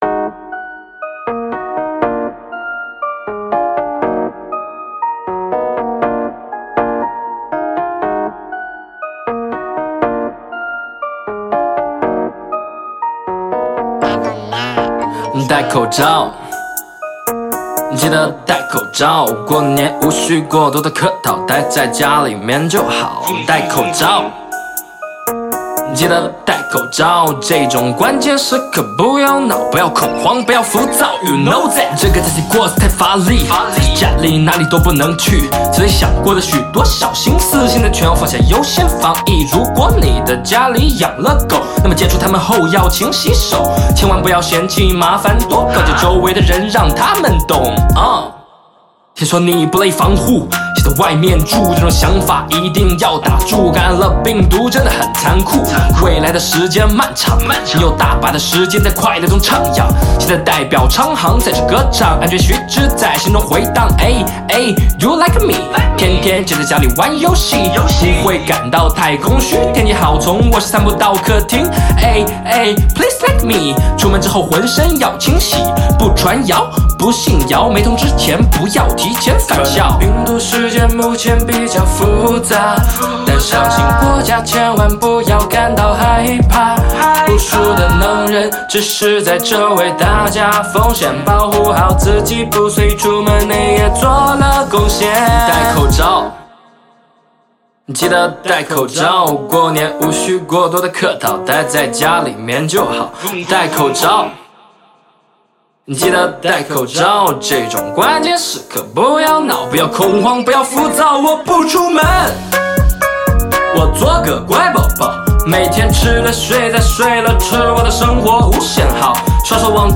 采用了时下年轻人喜欢的说唱形式